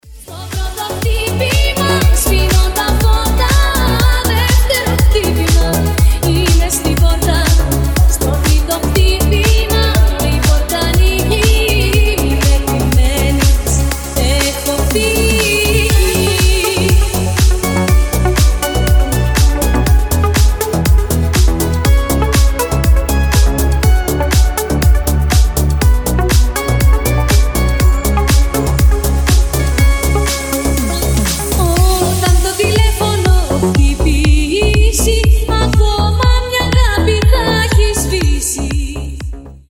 • Качество: 320, Stereo
deep house
retromix
nu disco